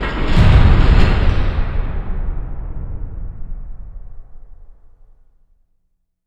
LC IMP SLAM 3B.WAV